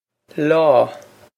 lá law
This is an approximate phonetic pronunciation of the phrase.